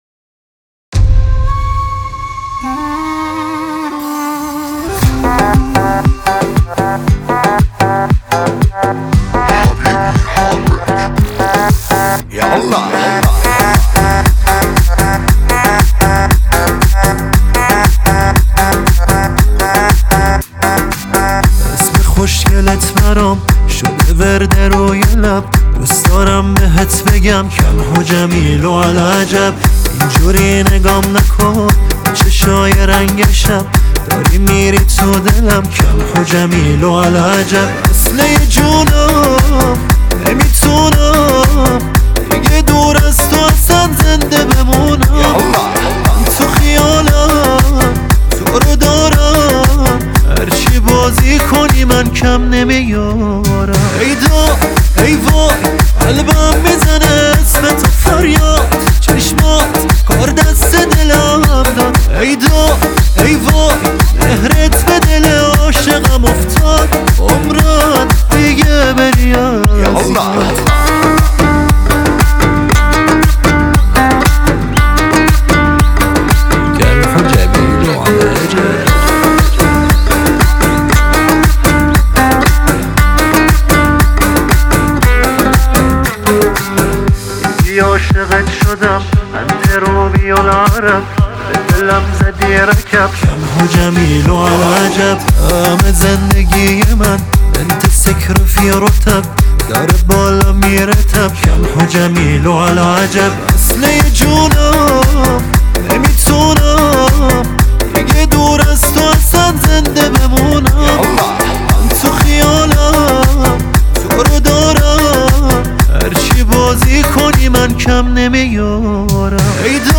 دانلود آهنگ شاد جدید